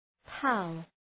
Προφορά
{pæl}